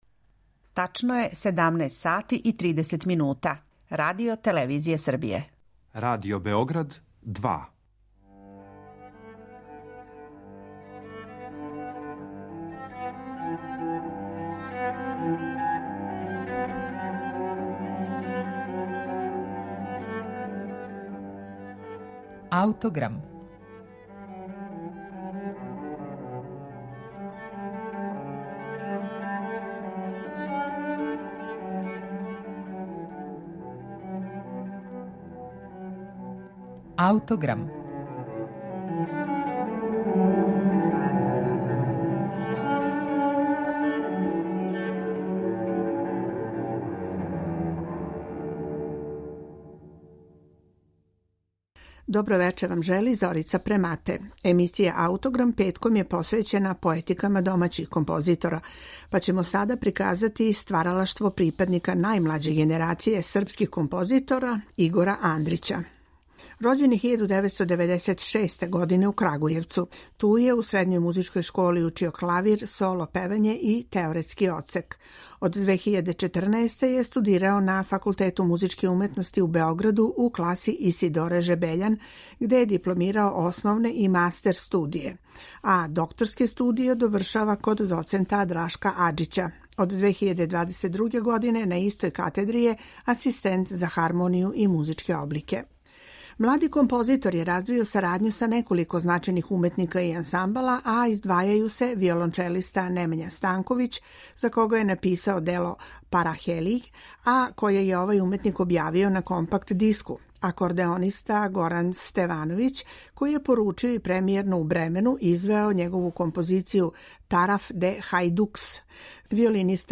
фантазија за виолину и гудаче